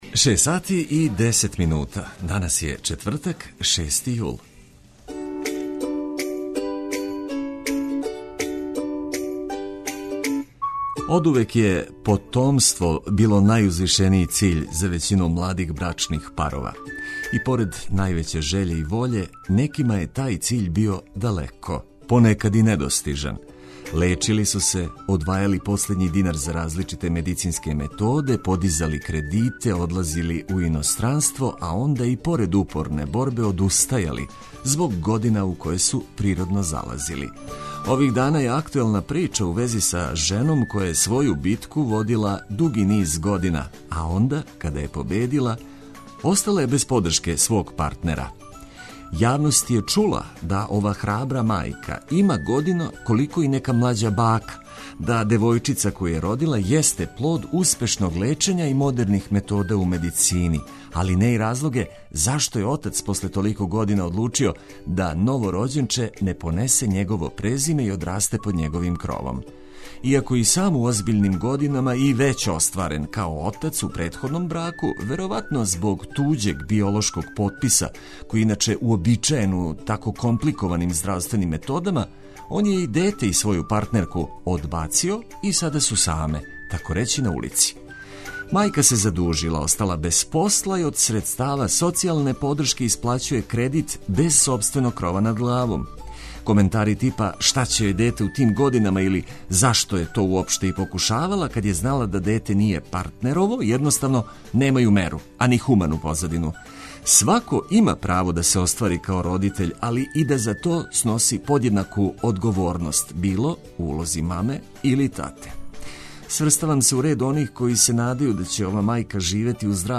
Летње освежење у виду ведрих тонова са свих страна света уз корисне и важне информације - то је укратко наша понуда за ово јутро.